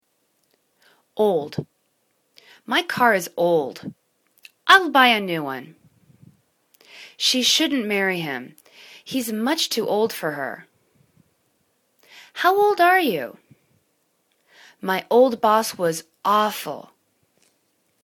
old    /o:ld/    adj